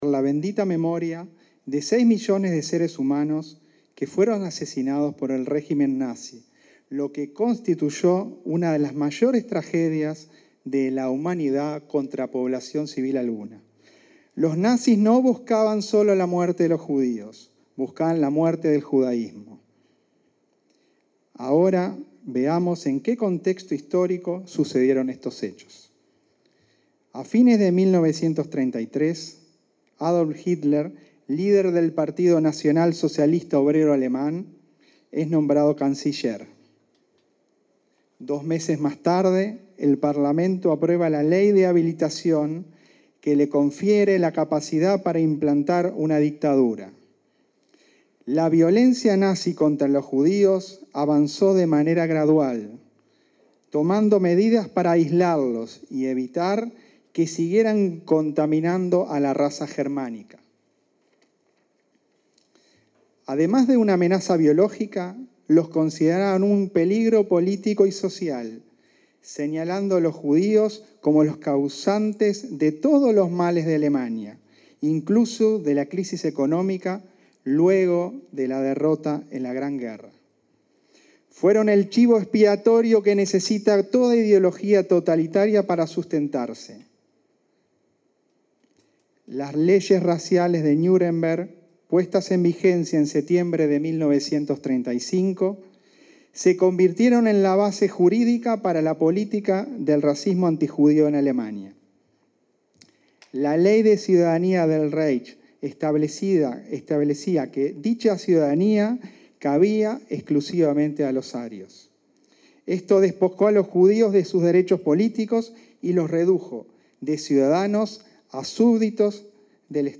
Conferencia de prensa por el 83.° aniversario de la Noche de los Cristales Rotos